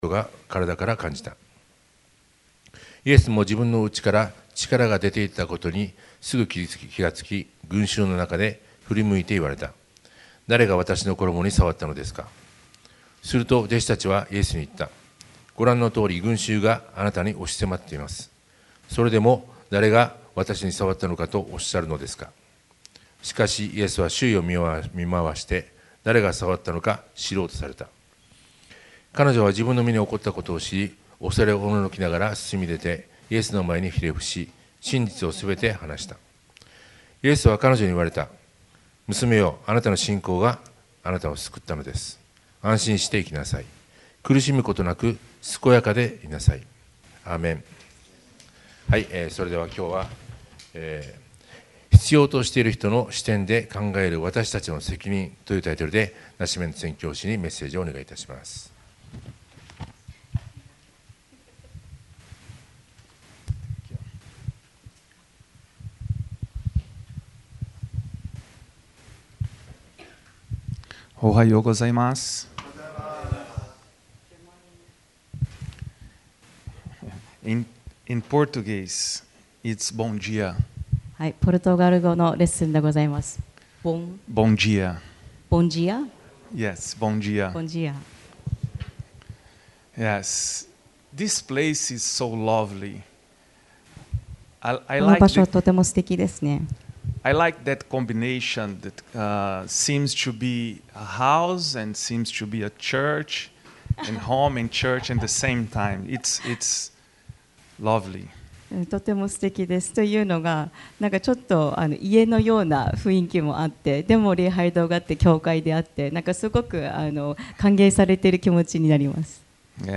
2026年3月8日礼拝メッセージ